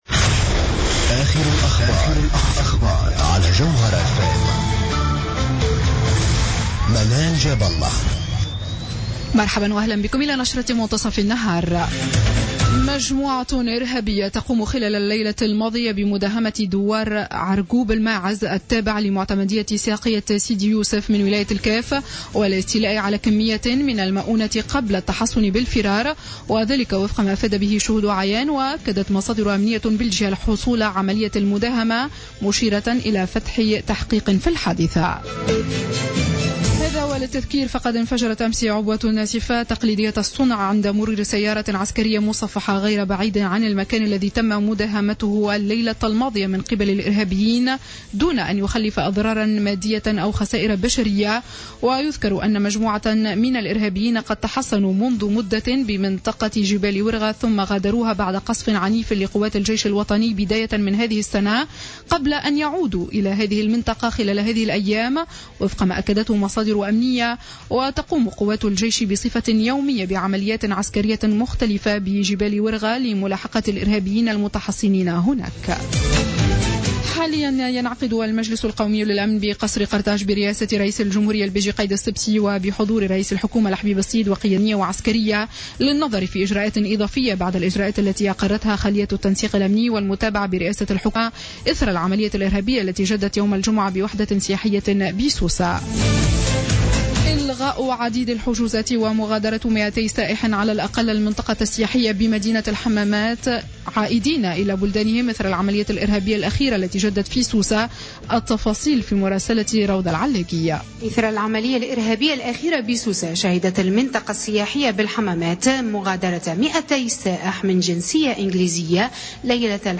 نشرة أخبار منتصف النهار ليوم الأحد 28 جوان 2015